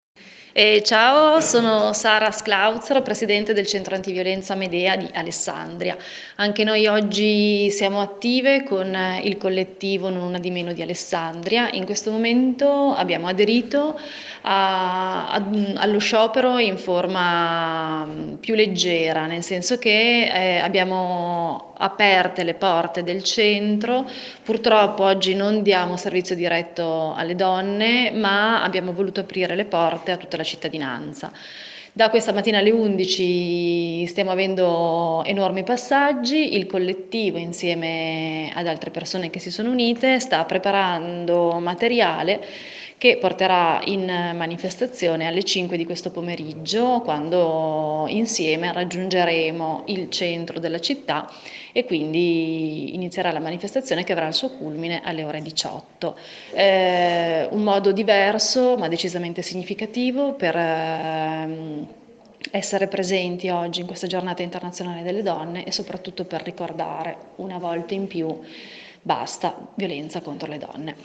la promessa delle operatrici e delle partecipanti all’open day del Centro Antiviolenza Me.Dea nel pomeriggio (ascolta il contributo audio);